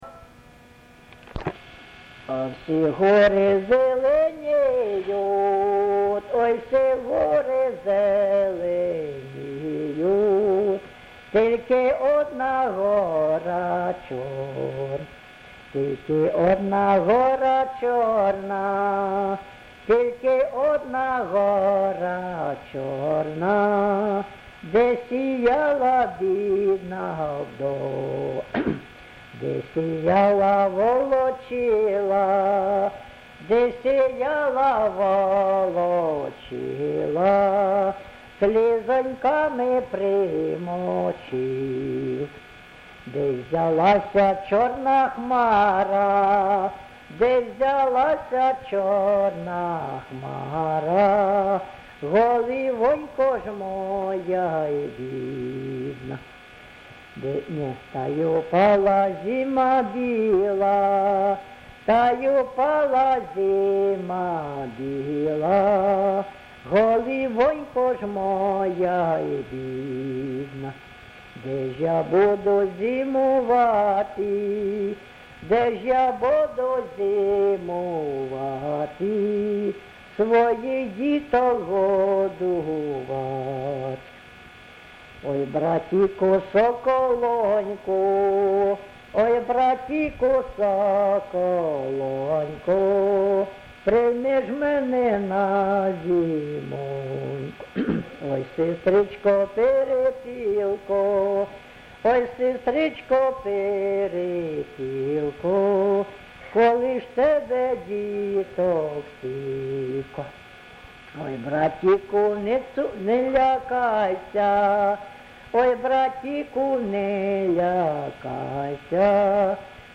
ЖанрПісні з особистого та родинного життя, Сирітські
Місце записум. Антрацит, Ровеньківський район, Луганська обл., Україна, Слобожанщина